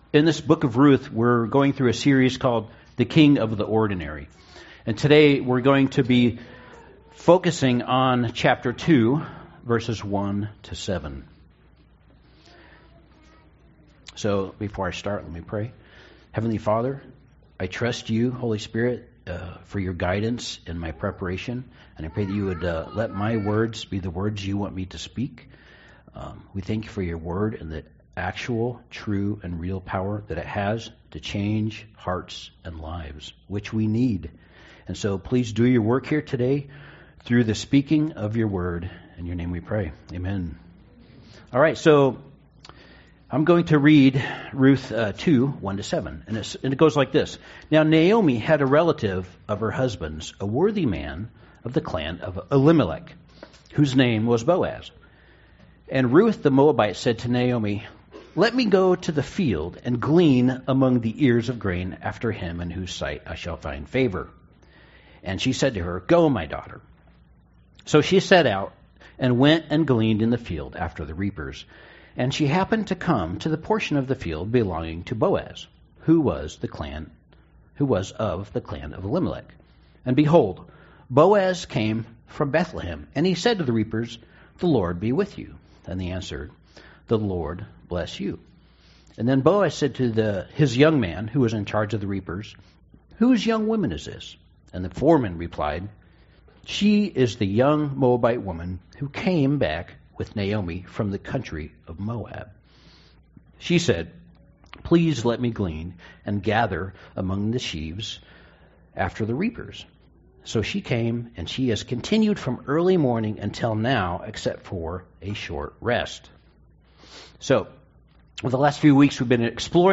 Ruth 2:1-7 Service Type: Sunday Service Darkness